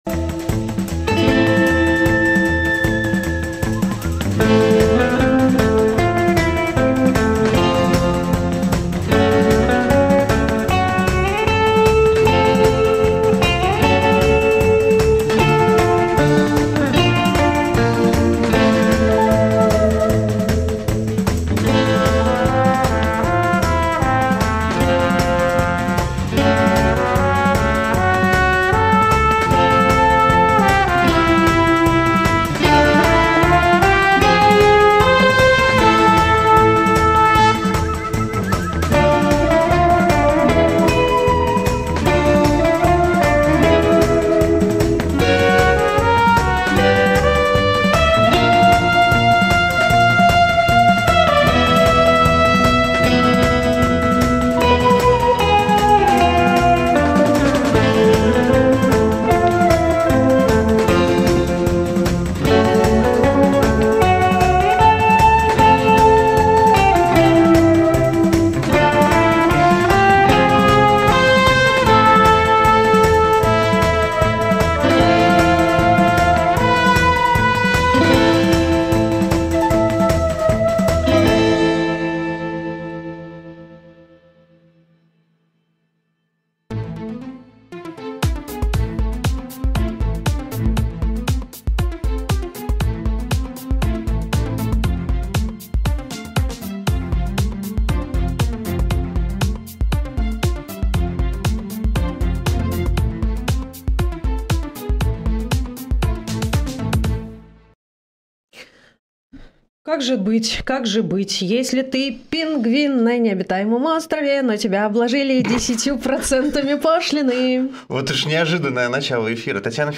Утренний эфир с гостями
Обсудим с экспертами в прямом эфире все главные новости.